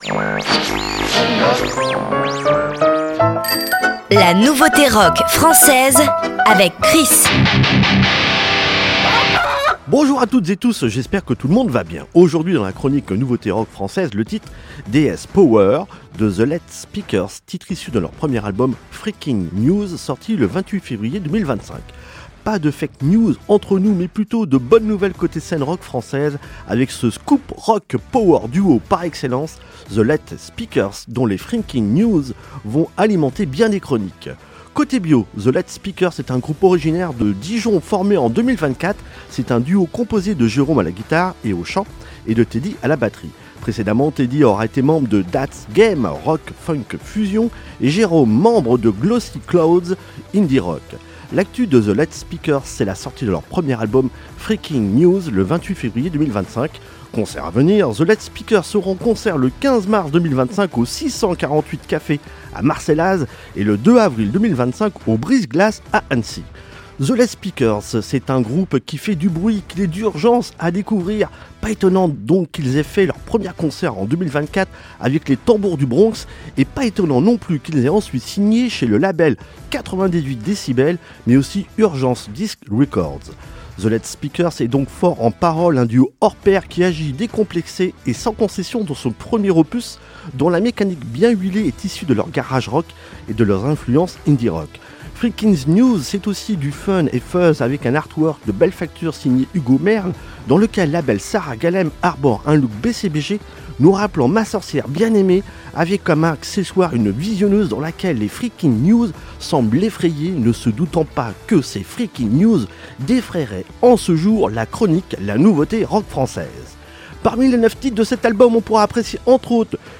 power duo par excellence